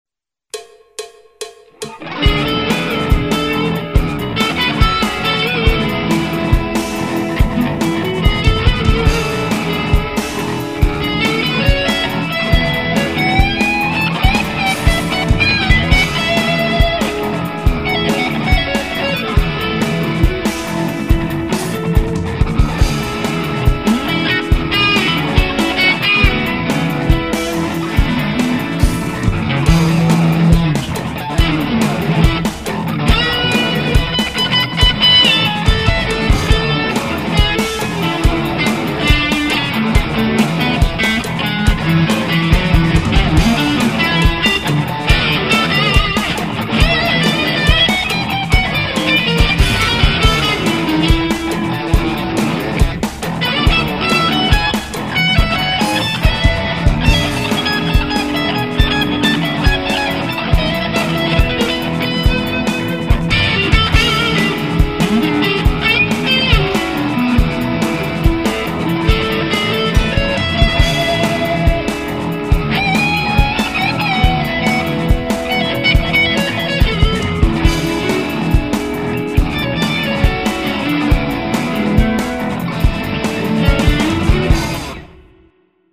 Ce qui m'a fait parler des relatives mineurs/majeurs c'est le fait qu'il avait dit qu'il avait utiliser les gammes mineurs/ Majeure de MI ,comme le nom du defi est "Rock Fusion en Mi" je me suis dit qu'il allait devoir utiliser l'une de ces gammes pour rester dans la tonalité .
En ce moment sur guitare électrique et Paul Beuscher...